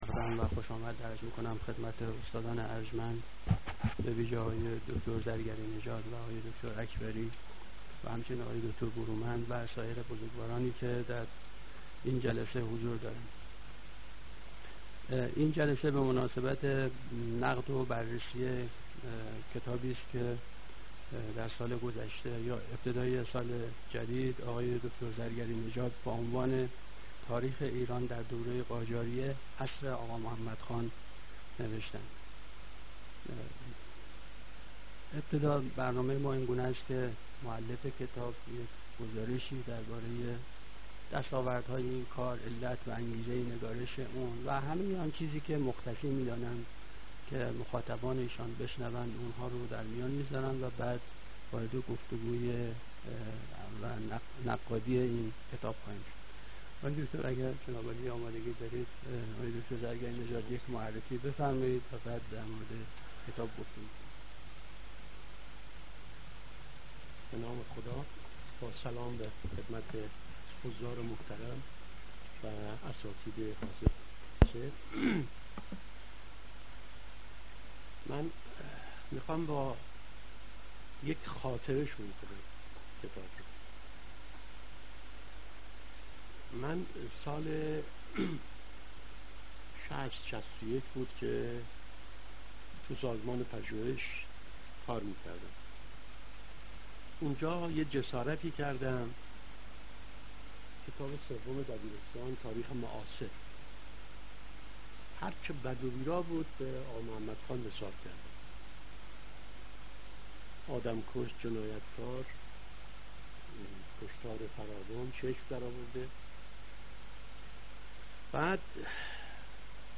زمان برگزاری: چهارشنبه ۱۸ بهمن از ساعت ۱۴:۳۰ الی ۱۶:۳۰ محل برگزاری: سالن اندیشه